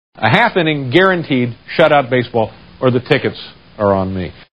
Category: Television   Right: Personal
Tags: David Letterman Late Night David Letterman clip David Letterman audio tv show sounds